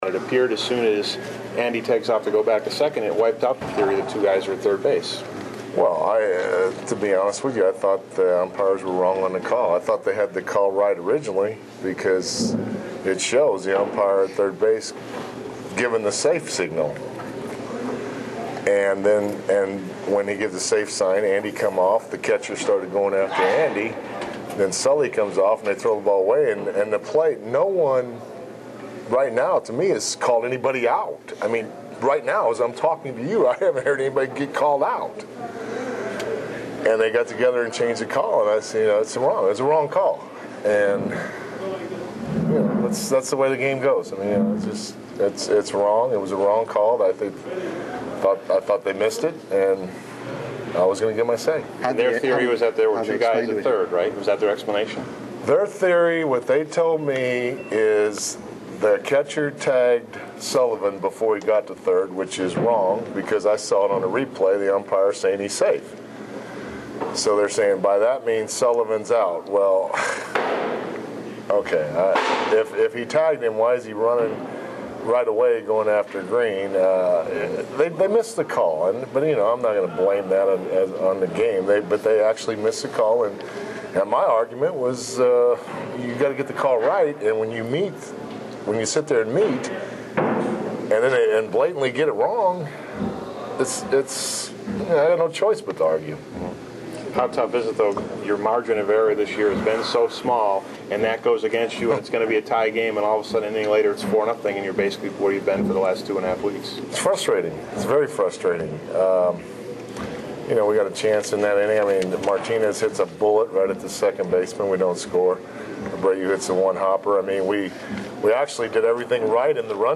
postgame gaggle